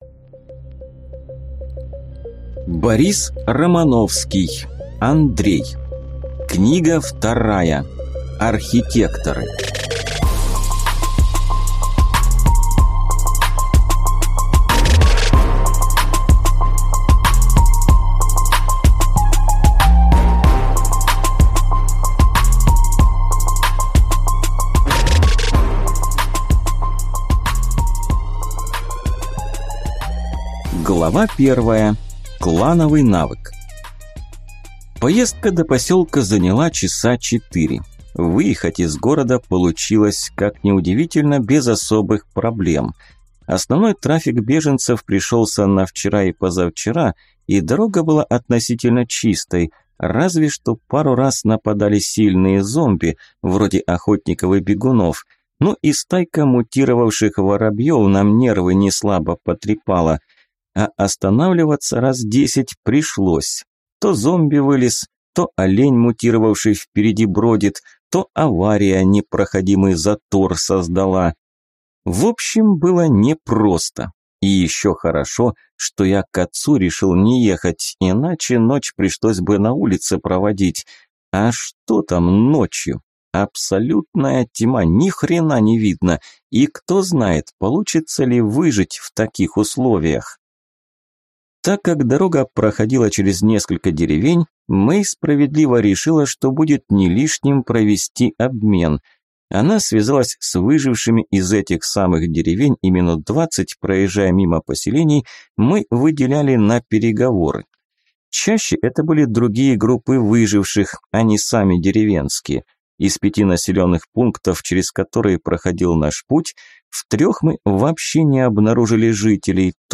Аудиокнига Архитекторы | Библиотека аудиокниг